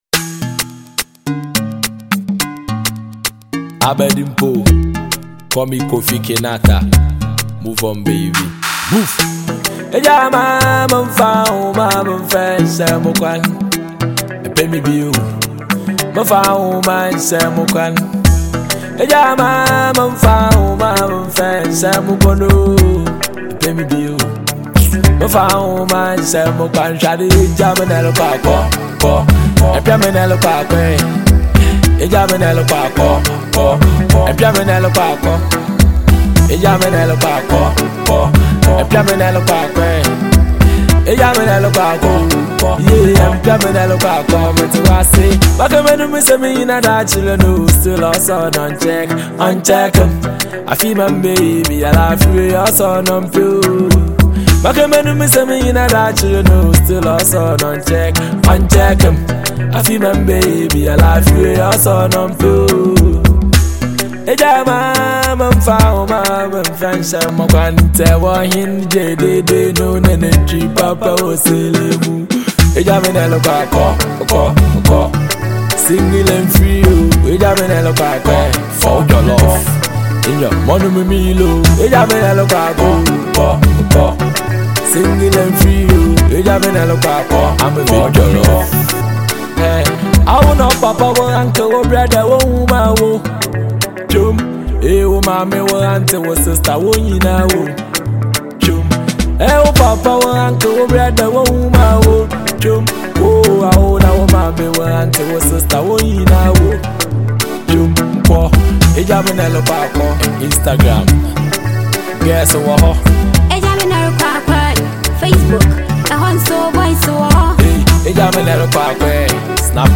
rapper
afro-pop